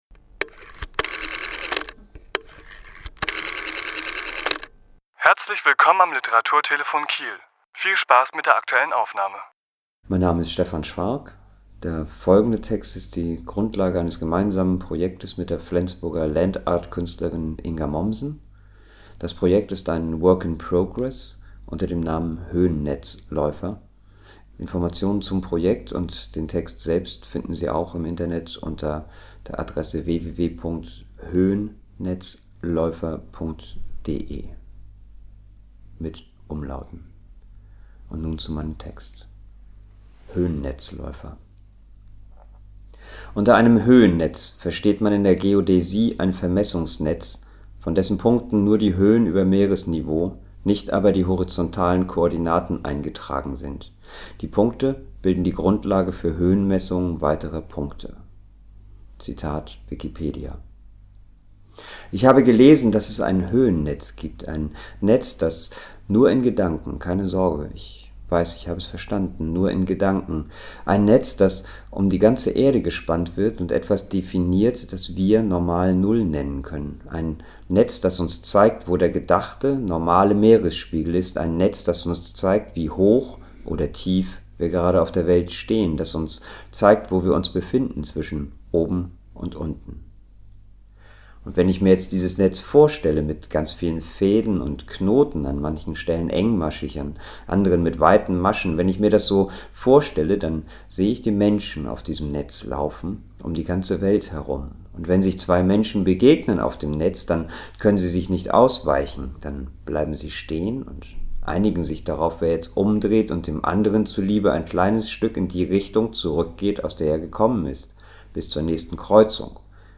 Autor*innen lesen aus ihren Werken